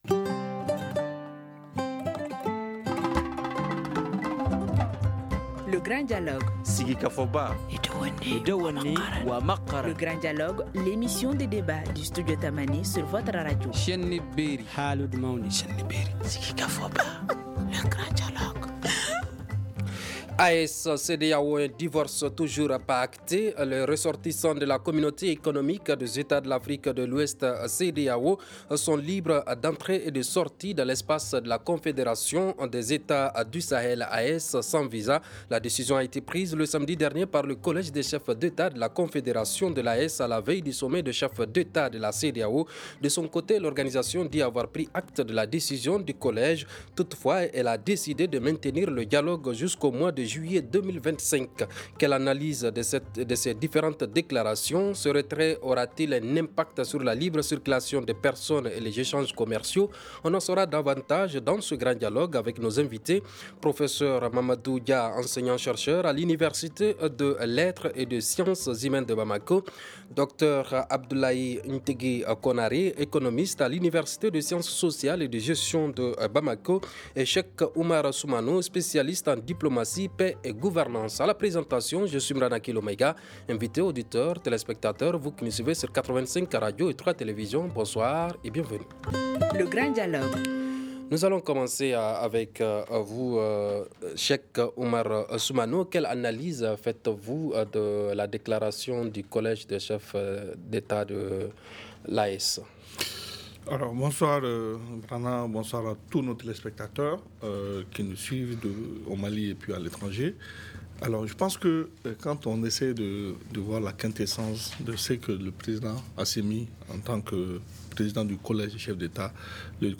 On en saura davantage dans ce Grand Dialogue avec nos invités :